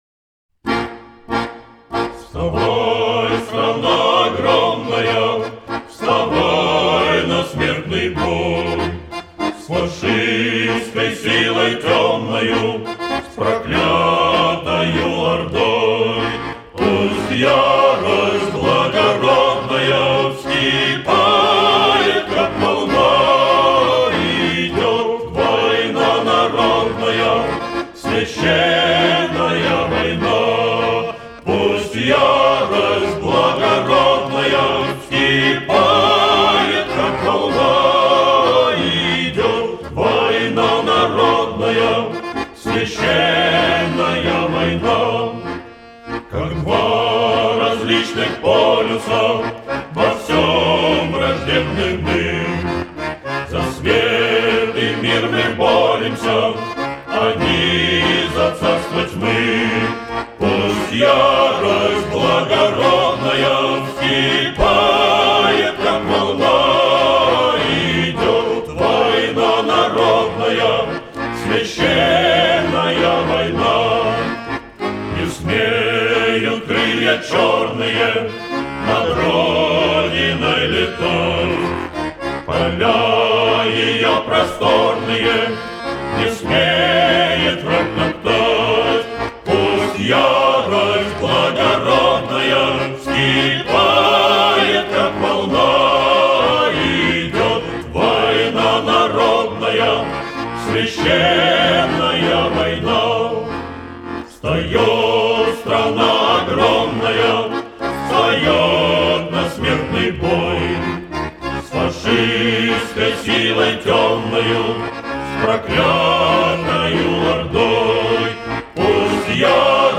Хор имени Александрова